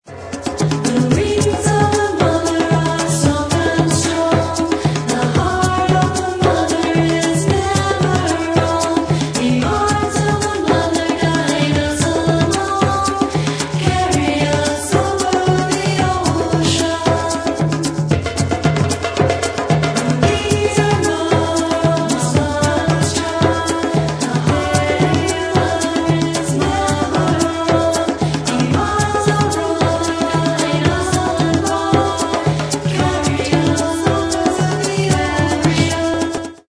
(descant with Goddess names)